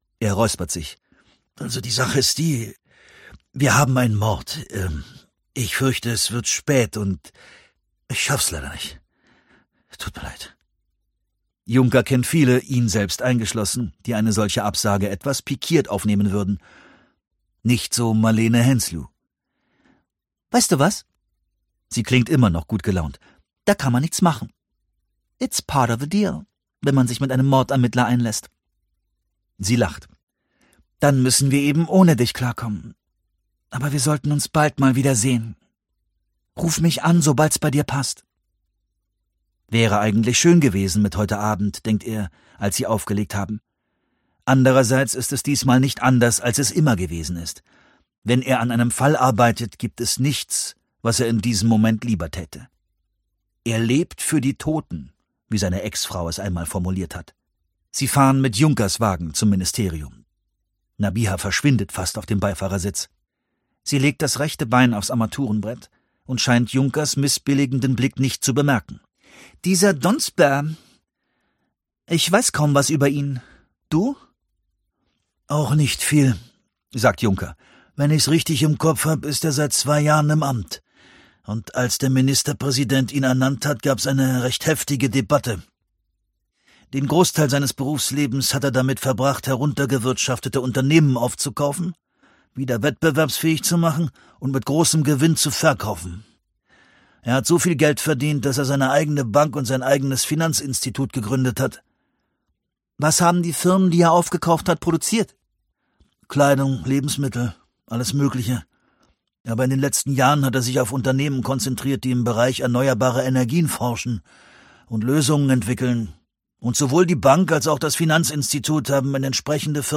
Stefan Kaminski (Sprecher)
leicht gekürzte Lesung